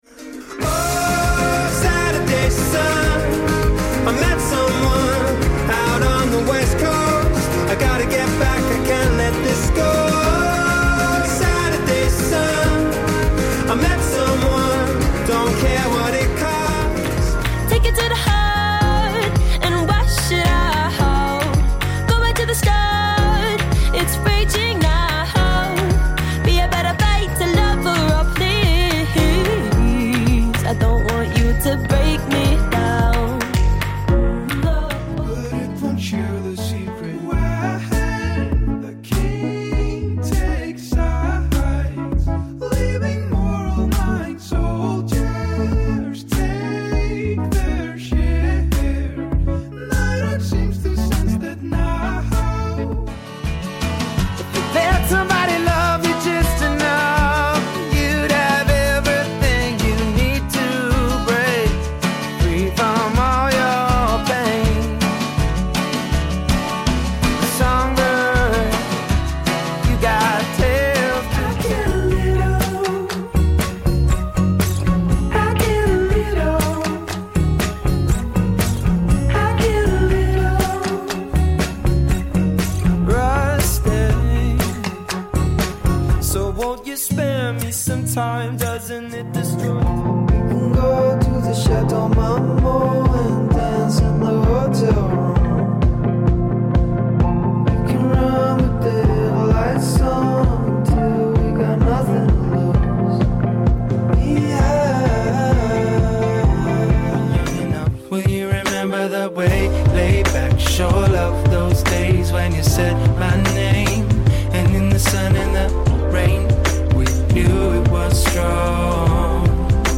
Morning Motivation